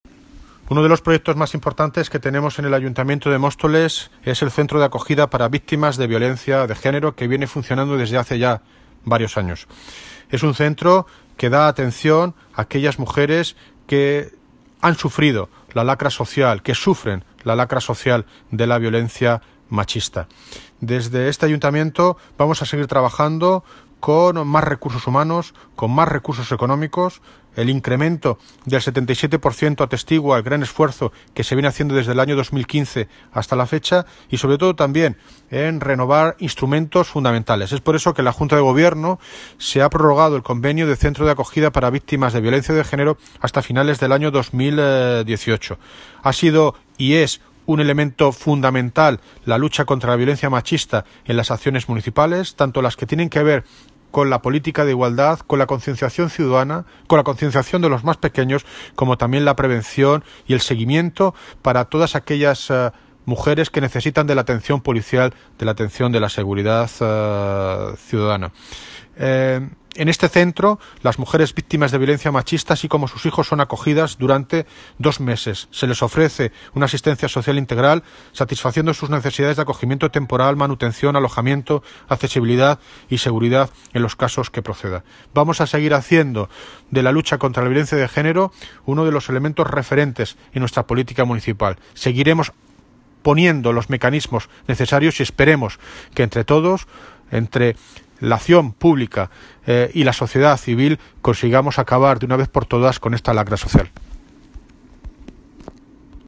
Audio - David Lucas (Alcalde de Móstoles) Sobre Centro de Acogida violencia de género